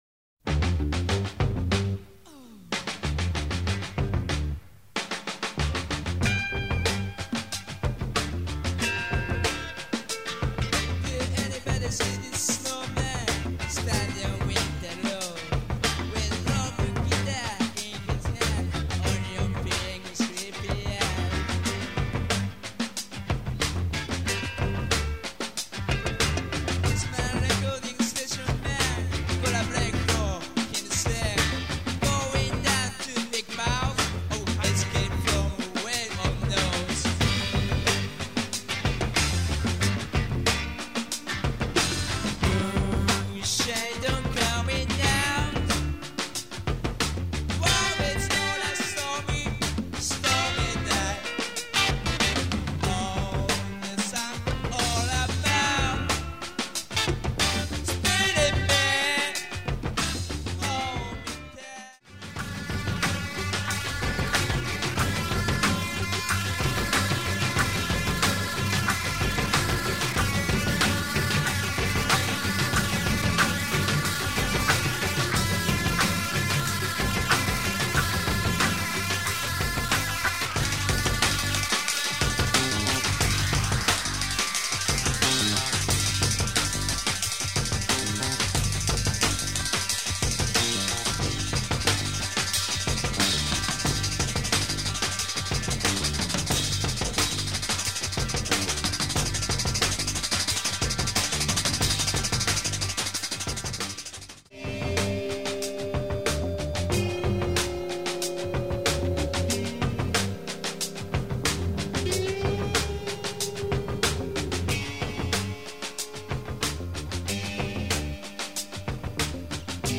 Killer breaks, psych groove and electronics.